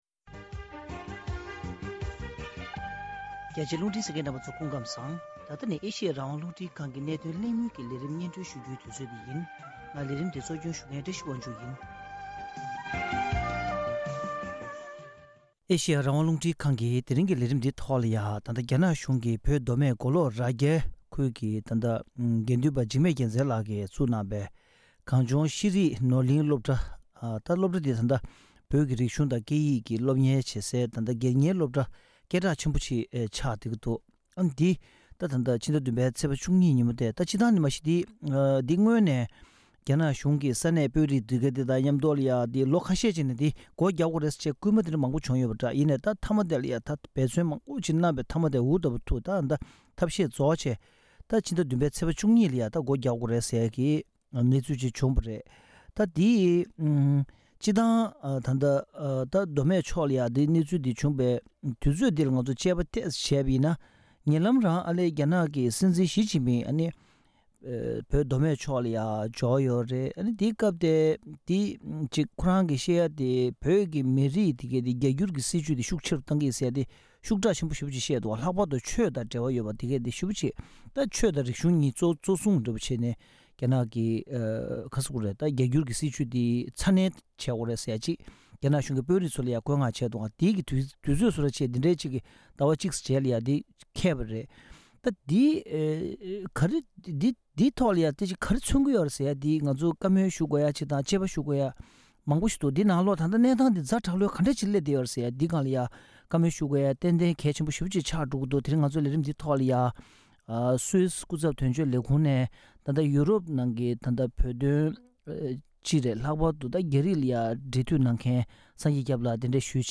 རྒྱ་ནག་གཞུང་གིས་བོད་མདོ་སྨད་མགོ་ལོག་ནང་གནས་པའི་གངས་ལྗོངས་ཤེས་རིག་ནོར་གླིང་ལས་རིགས་སློབ་གྲྭ་སྒོ་བརྒྱབ་བཅུག་པའི་གནད་དོན་གླེང་མོལ།